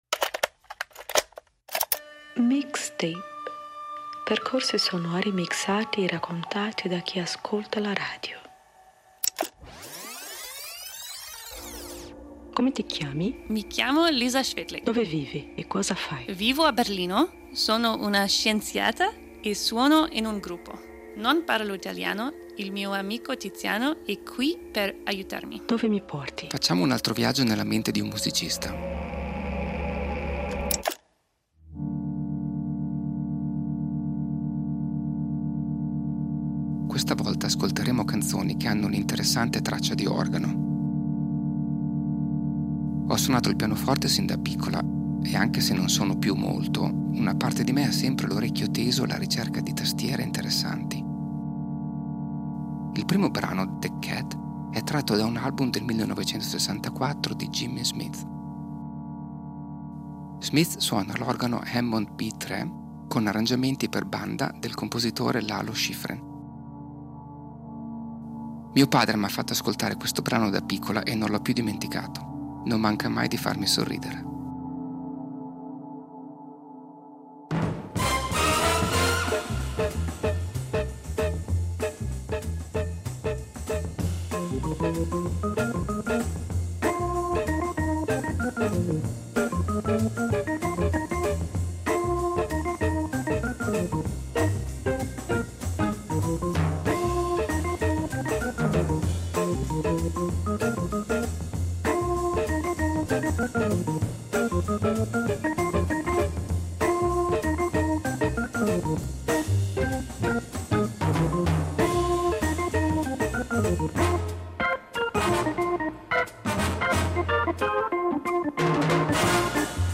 Se ti fermi ad ascoltare con attenzione, ti accorgerai che quello strumento dà al pezzo un suono e un senso unici.